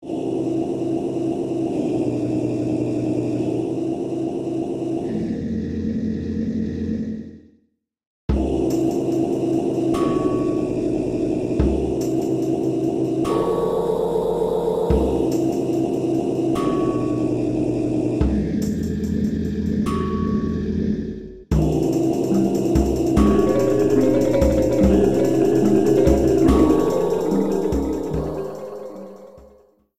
Slightly reduced length to 30 seconds, added fadeout.